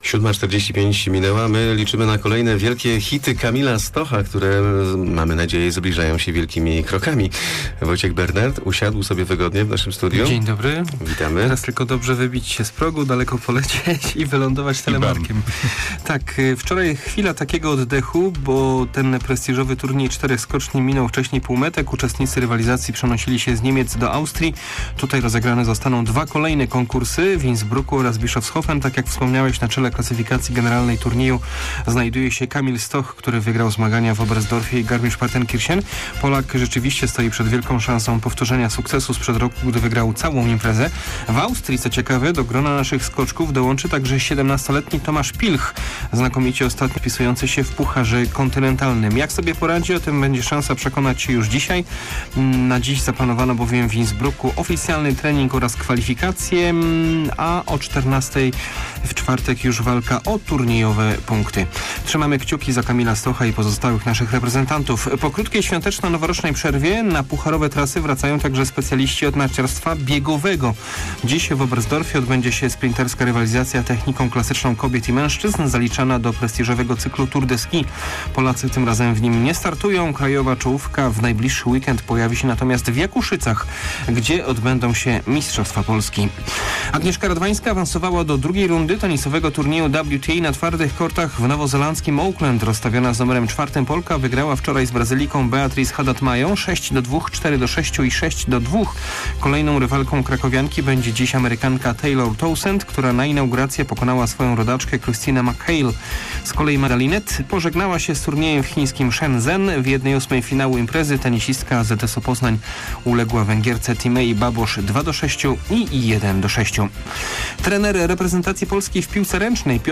03.01 serwis sportowy godz. 7:45